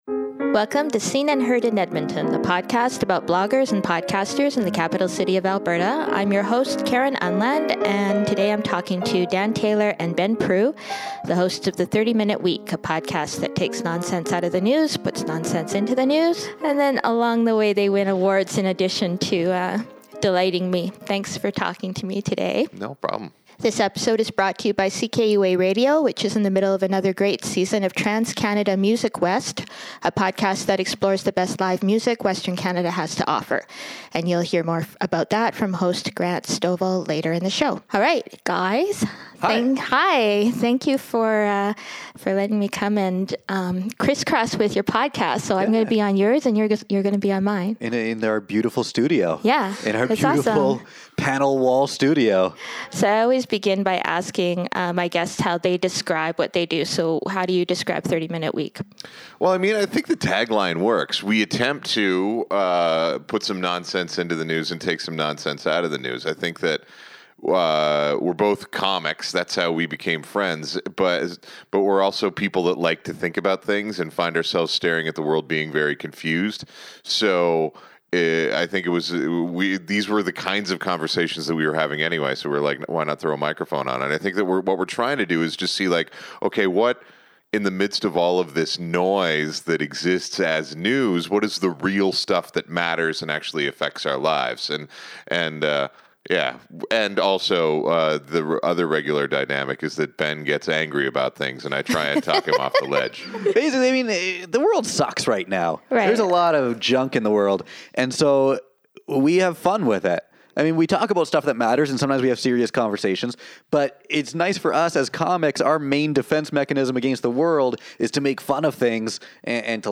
We laughed a lot in this episode